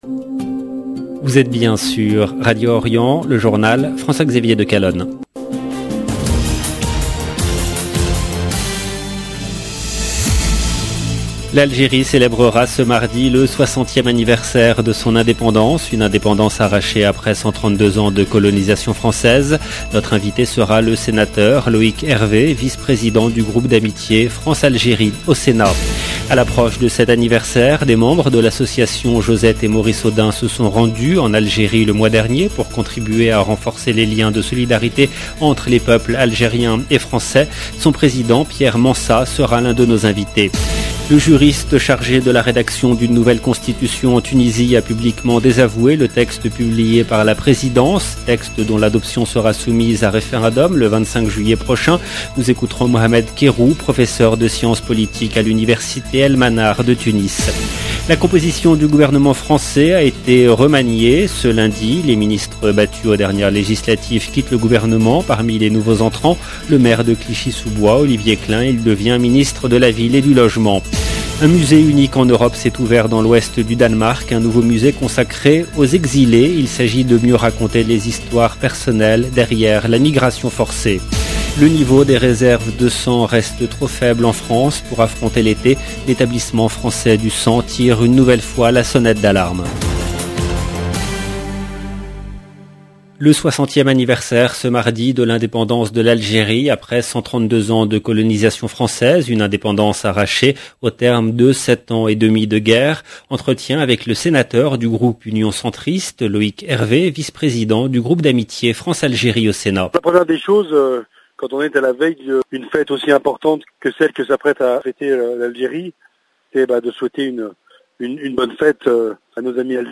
Notre invité sera le sénateur Loïc Hervé, vice président du groupe d’amitié France Algérie au Sénat.